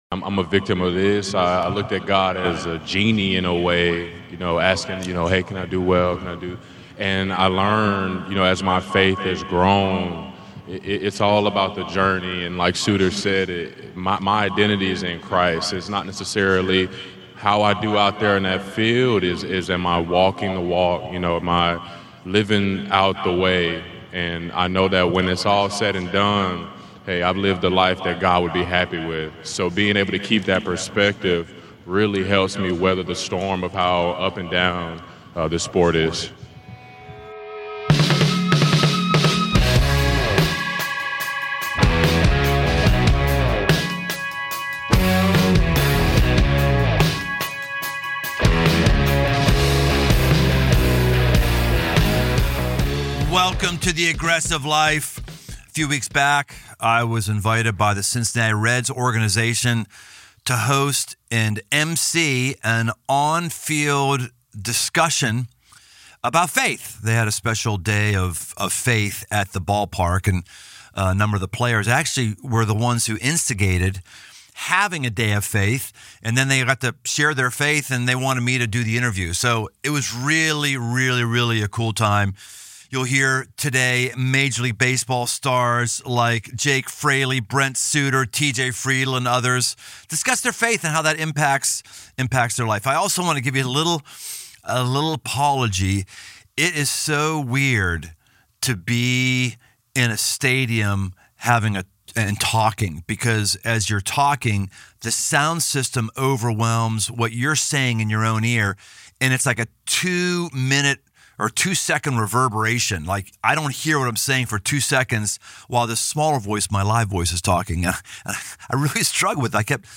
Major League Faith: On the Field with the Cincinnati Reds' Brent Suter, Jake Fraley, TJ Friedl, Will Benson, Emilio Pagan and Tyler Stephenson—Live from Great American Ballpark